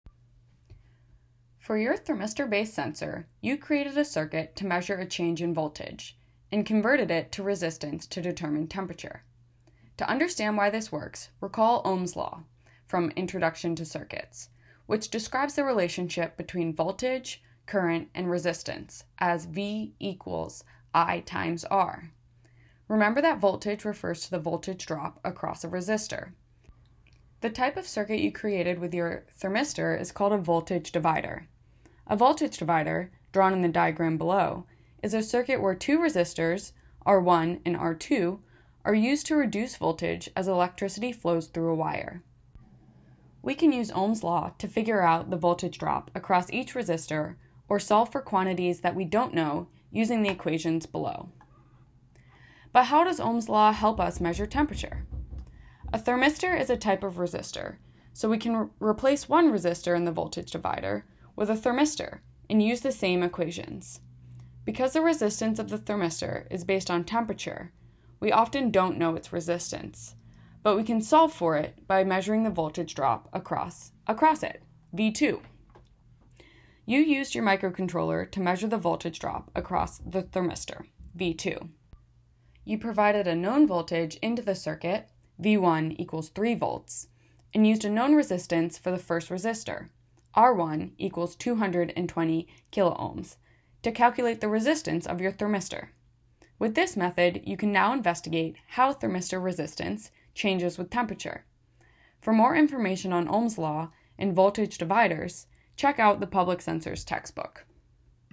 Audio Read Along: Bonus